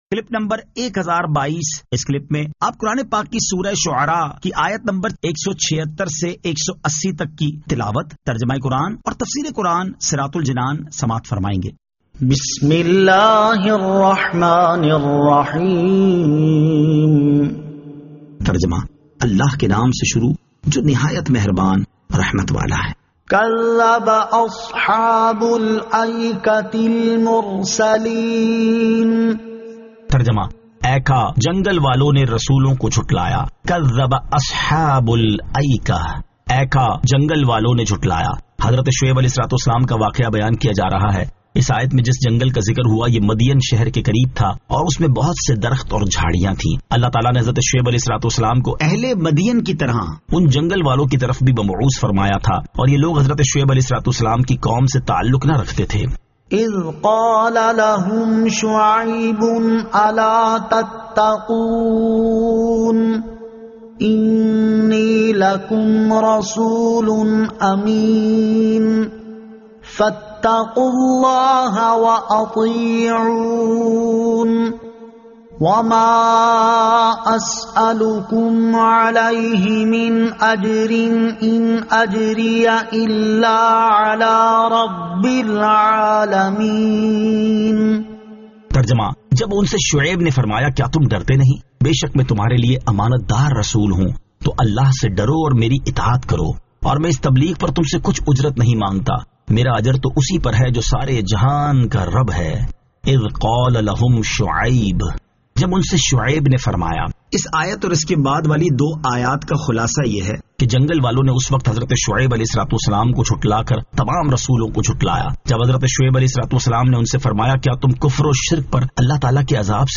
Surah Ash-Shu'ara 176 To 180 Tilawat , Tarjama , Tafseer